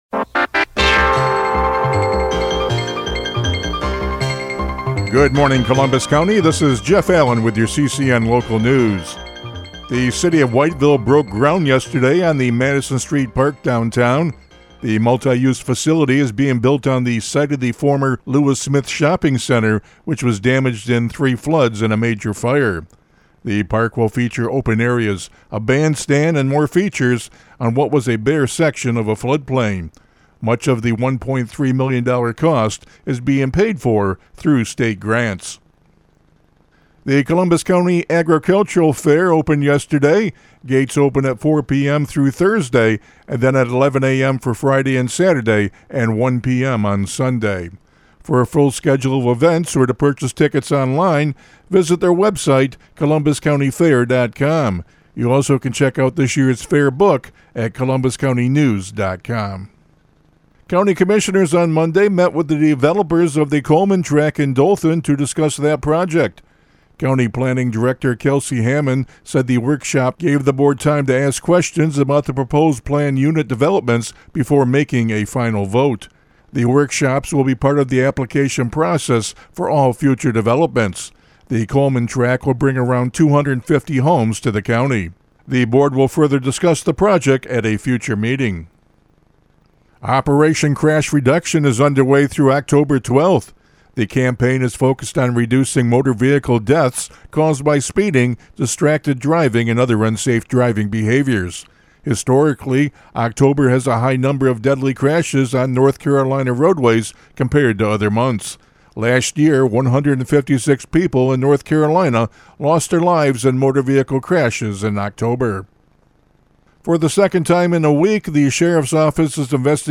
CCN Radio News — Morning Report for October 8, 2025